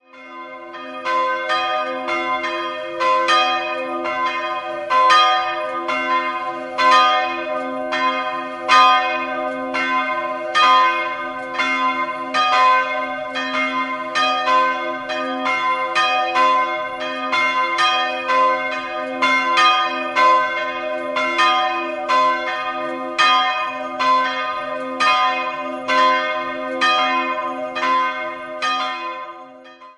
Megmannsdorf, Filialkirche St. Johannes Baptist Die kleine Filialkirche des zur Pfarrei Pondorf gehörenden Ortes Megmannsdorf dürfte schon ein recht alter Bau aus romanischer Zeit sein. Im 17. Jahrhundert gab es einen größeren Umbau und die Barockisierung. In den Jahren 1990/91 erfolgte eine gründliche Instandsetzung der Filialkirche. 2-stimmiges Kleine-Terz-Geläute: c''-es'' Beide Glocken wurden 1950 von Johann Hahn, Landshut, gegossen und erklingen in den Tönen c''-4 und es''-2.